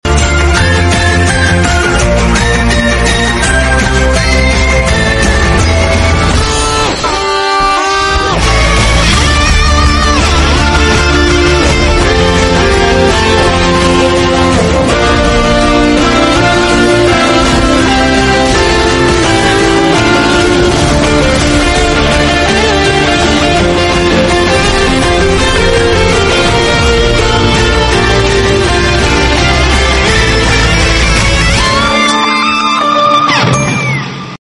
Guitar Cover